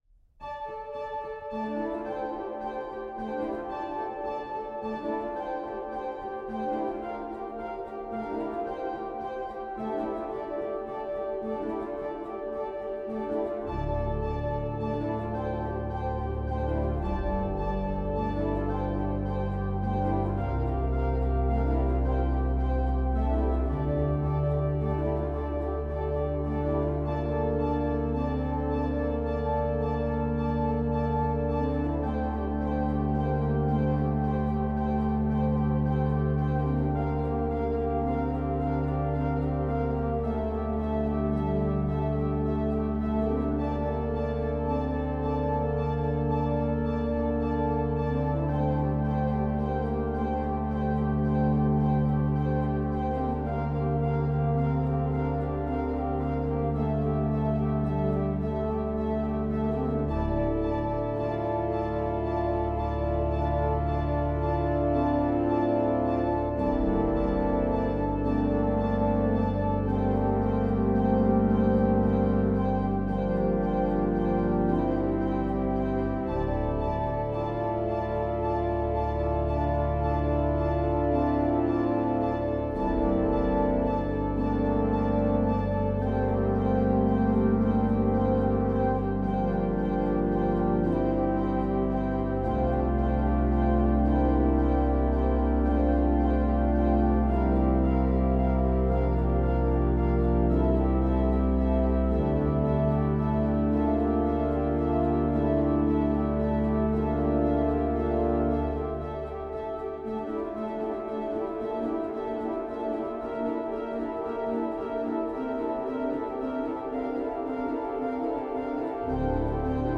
organ Duration
Repeating patterns run throughout.